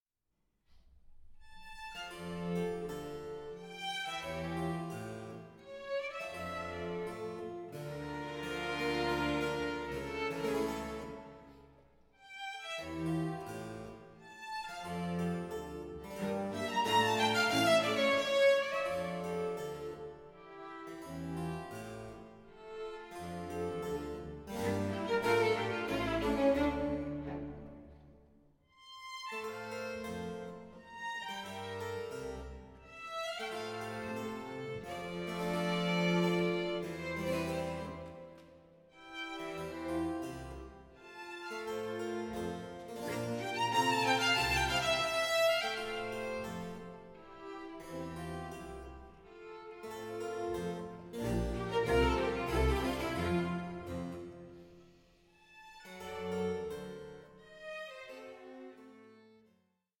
Soprano